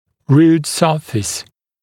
[ruːt ‘sɜːfɪs][ру:т ‘сё:фис]поверхность корня